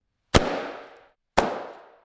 balloon popping
balloon bang bomb boom bubble burst explode explosion sound effect free sound royalty free Sound Effects